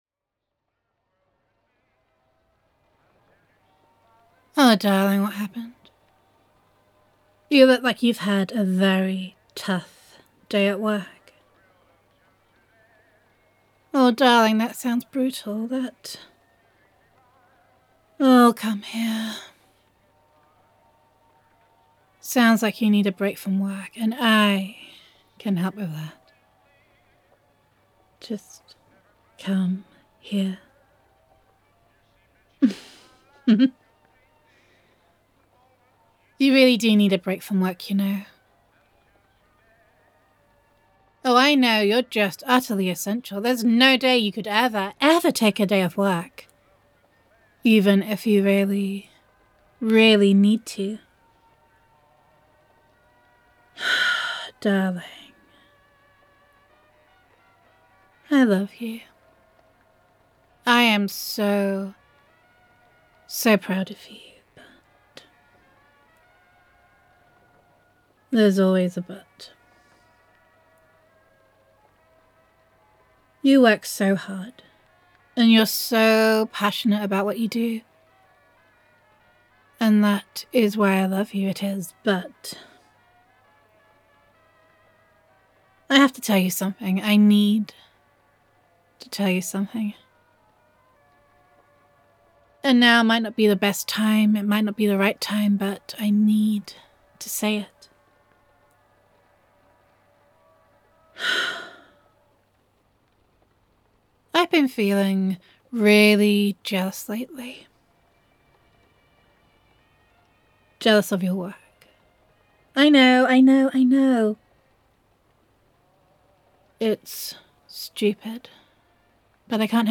[F4A]
[Wife Roleplay]